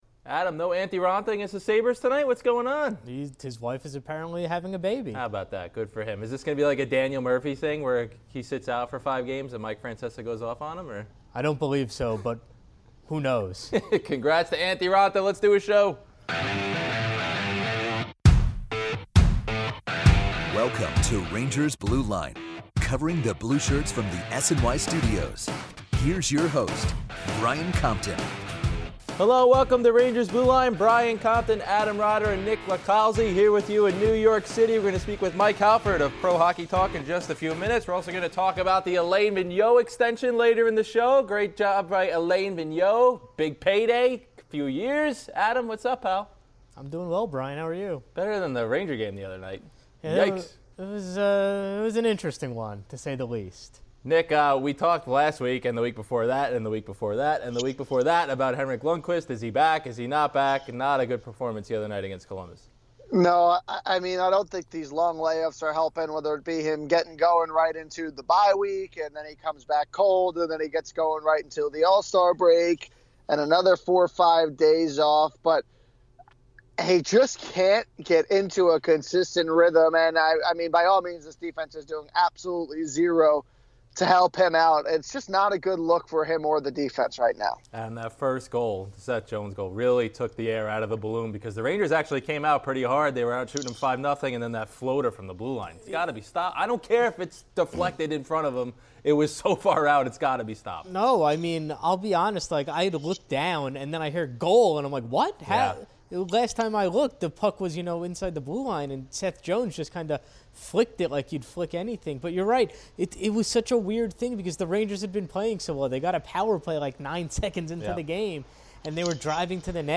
calls in to discuss Henrik Lundqvist and the evolving trade market.